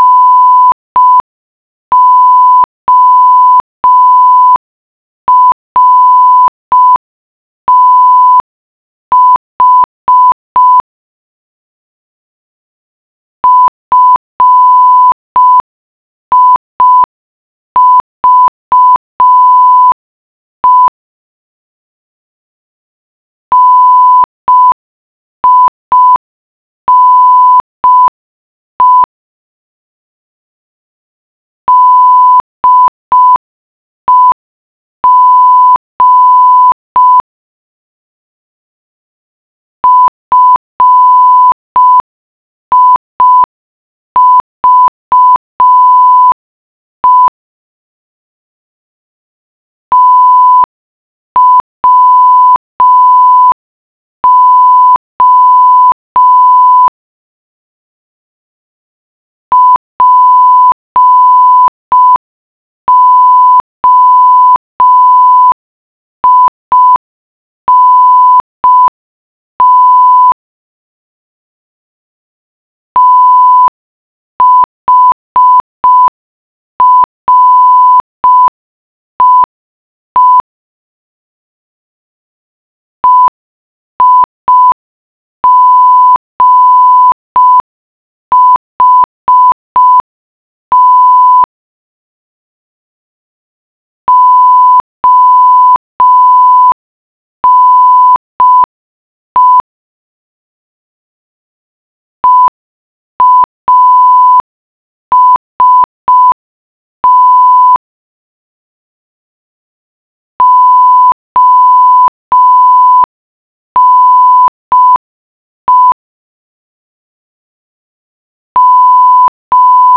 5 words/min (very slow), from the short wave radio!
Morse alphabet
morse5.wav